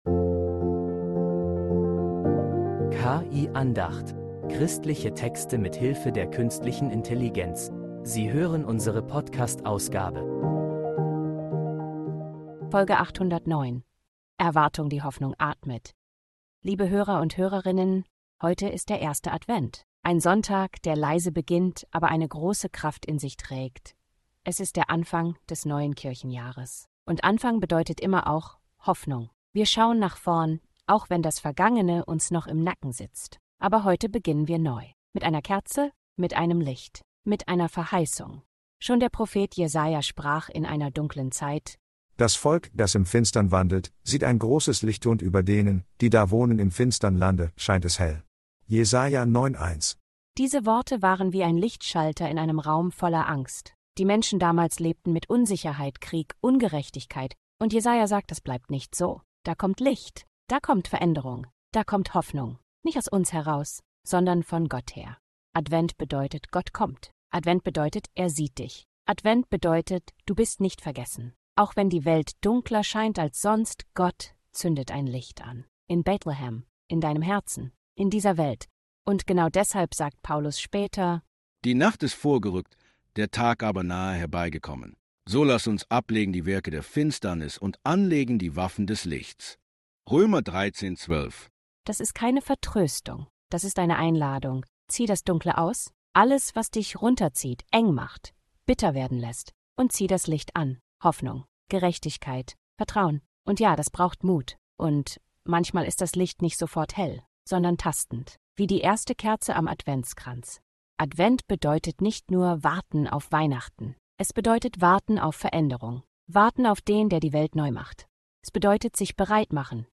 Andacht zum Sonntag.